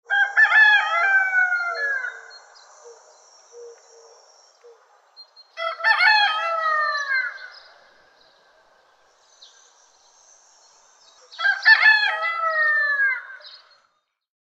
Rooster-crowing-3-times-sound-effect.mp3
rooster-crowing-3-times-sound-effect.mp3